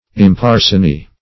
Search Result for " imparsonee" : The Collaborative International Dictionary of English v.0.48: Imparsonee \Im*par`son*ee"\, a. [OF. empersone.